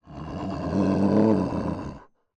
SFX_Wolf_Growl_01.wav